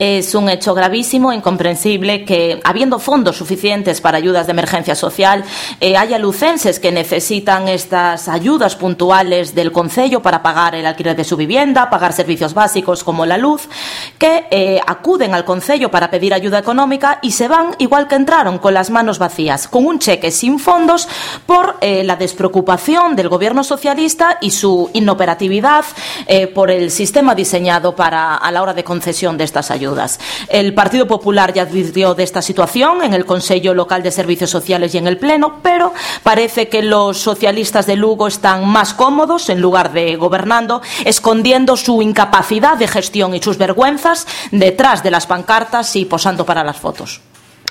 La concejala del Grupo Municipal del Partido Popular Isabel Devesa denunció esta mañana en rueda de prensa que el Ayuntamiento de Lugo está entregando cheques sin fondos para ayudas de emergencia social “por la despreocupación y la falta de sensibilidad del gobierno municipal”.